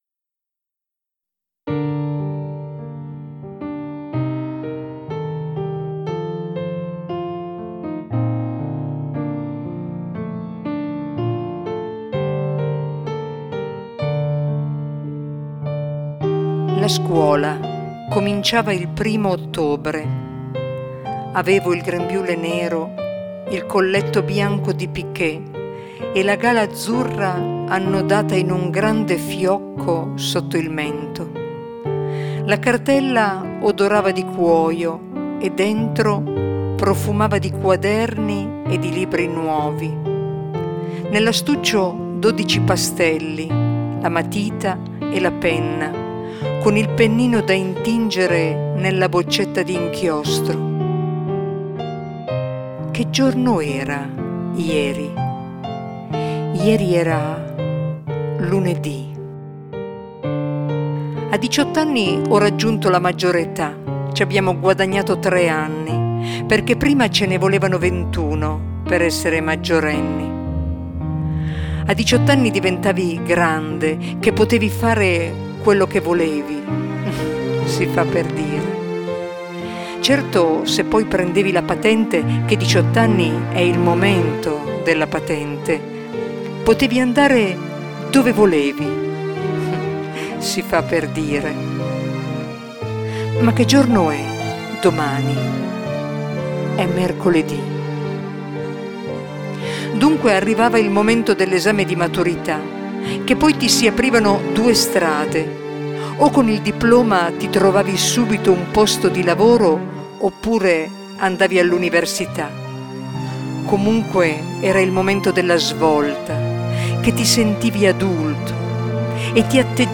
04-E-di-nuovo-lunedi-monologo.mp3